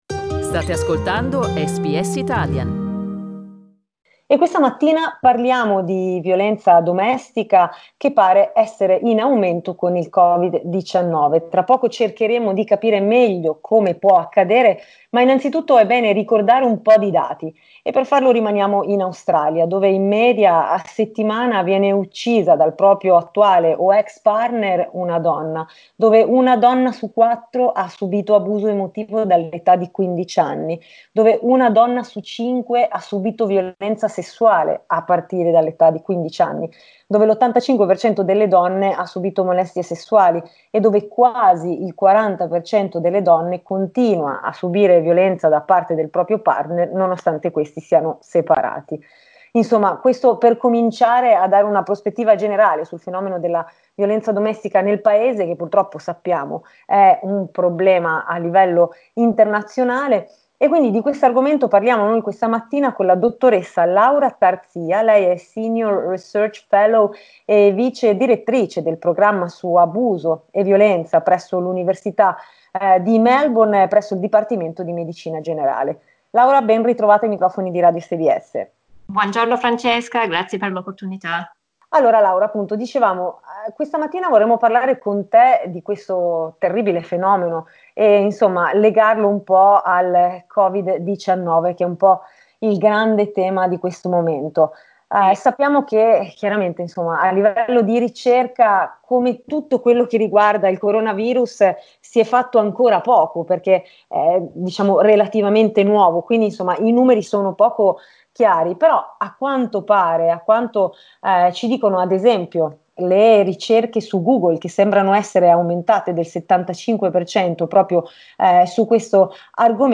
Abbiamo cercato di capirlo in quest'intervista